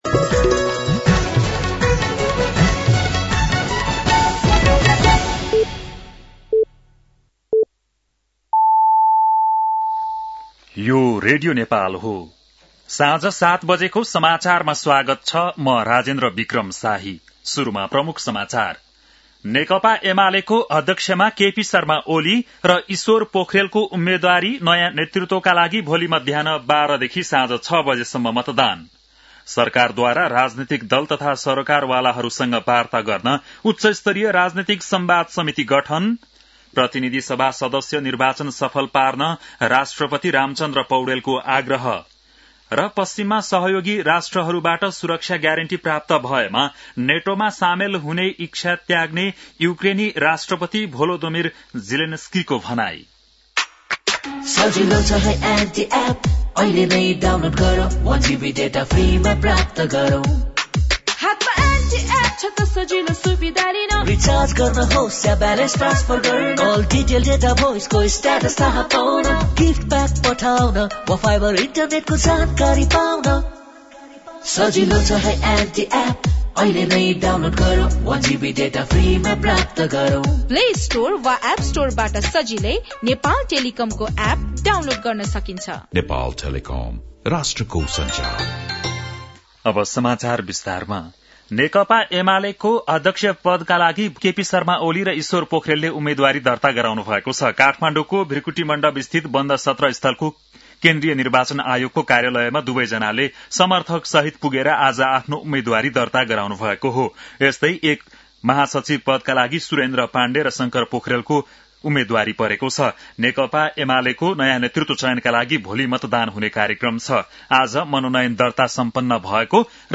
बेलुकी ७ बजेको नेपाली समाचार : २९ मंसिर , २०८२
7-pm-nepali-news-8-29.mp3